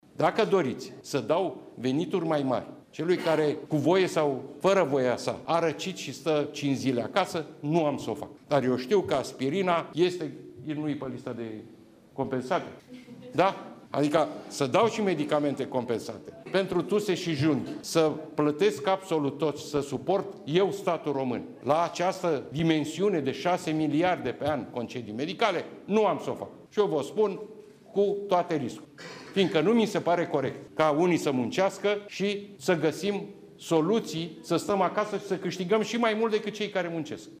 Marcel Ciolacu: „Nu mi se pare corect ca unii să muncească și să găsim soluții să stăm acasă și să câștigăm și mai mult decât cei care muncesc”